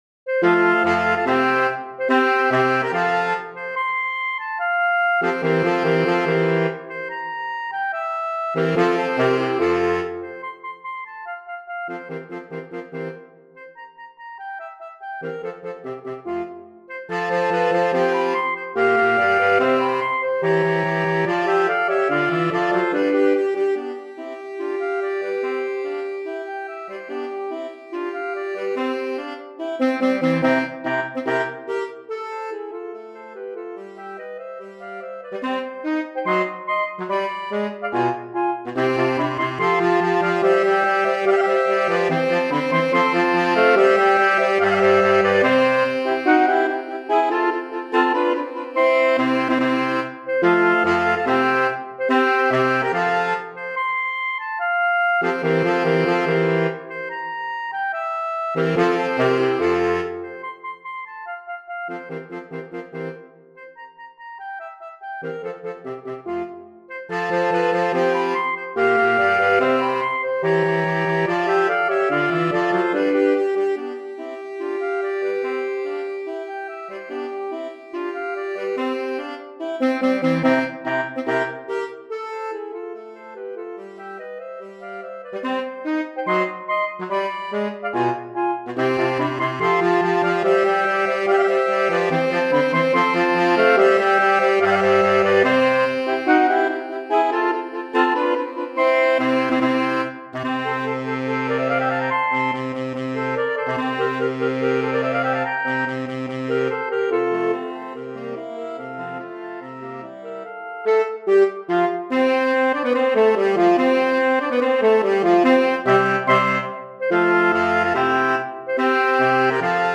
Saxophone Quartet SATB Format